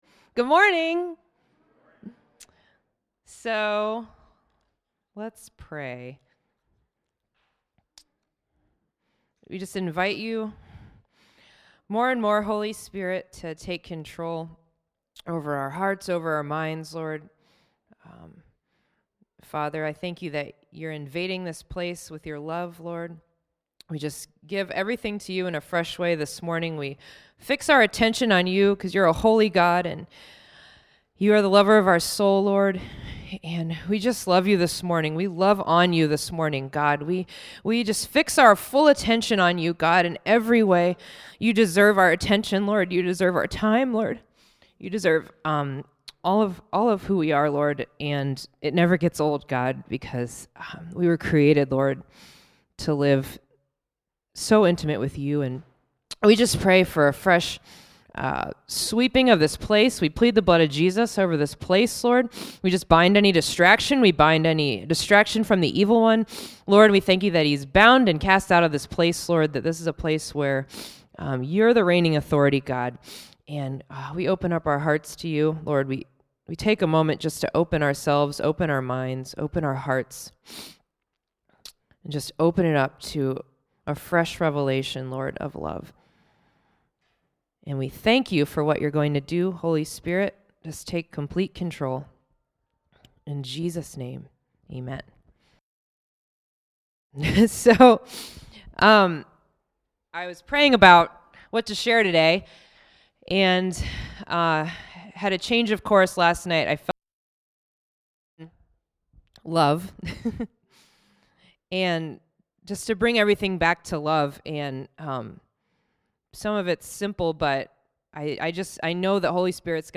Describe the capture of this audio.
(Excerpt from 5:50 – 15:00 shown at service)